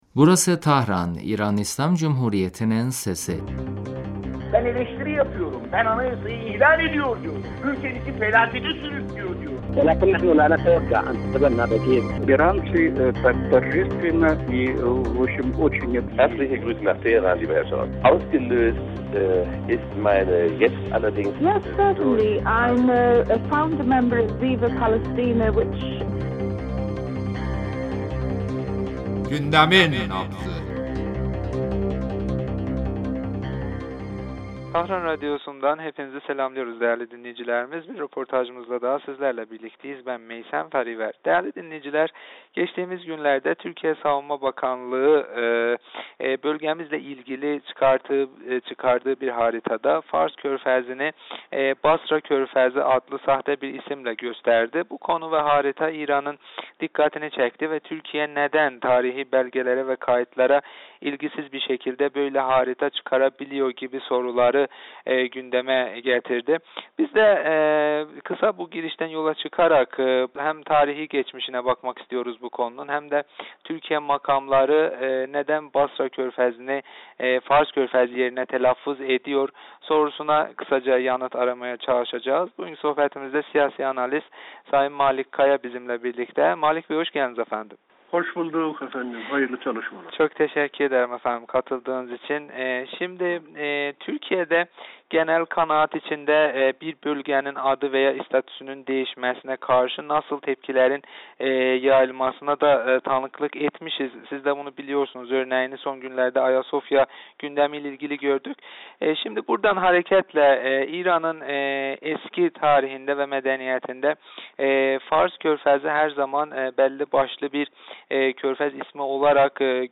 telefon görüşmesinde Türkiye Savunma Bakanlığının bölge ile ilgili çıkardığı haritada Fars Körfezi ismi yerine sahte bir ad kullanma skandalı üzerinde konuştuk.